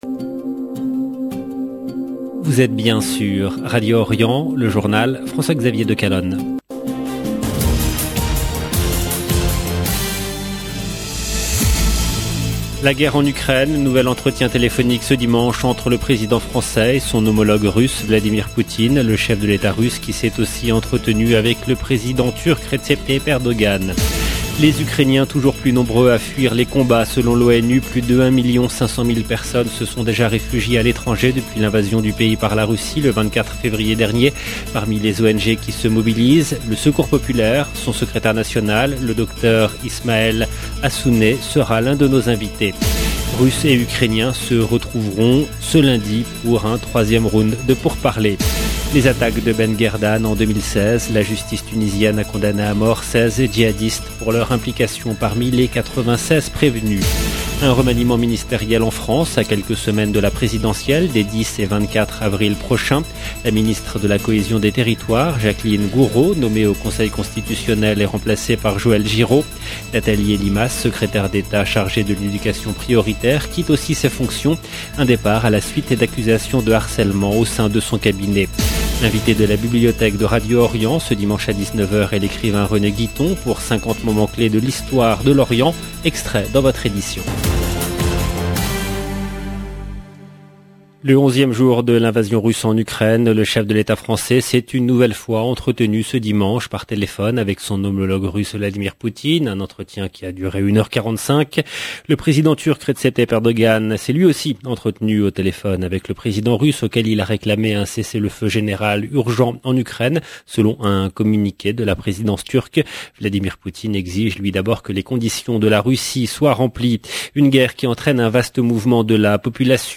EDITION DU JOURNAL DU SOIR EN LANGUE FRANCAISE DU 6/3/2022